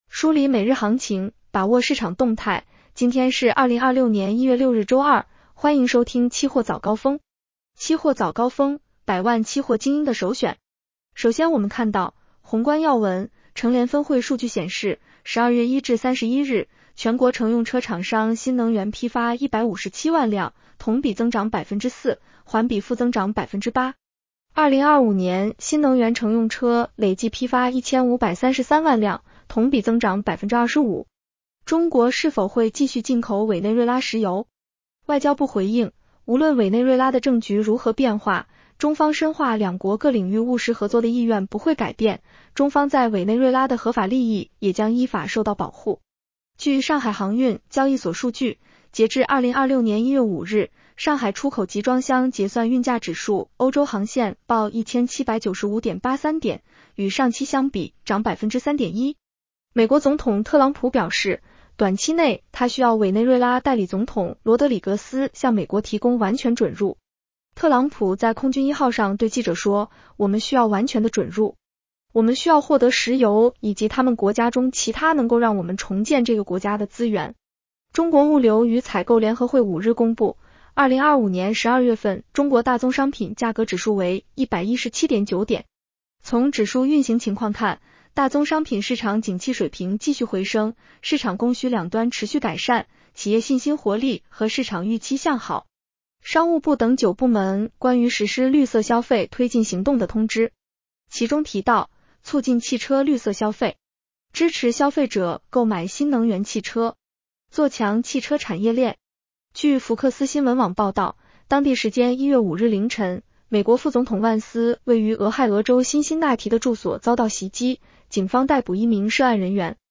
期货早高峰-音频版
期货早高峰-音频版 女声普通话版 下载mp3 热点导读 1.商务部等9部门：支持消费者购买新能源汽车，探索盘活闲置车辆增收。